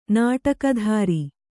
♪ nāṭakadhāri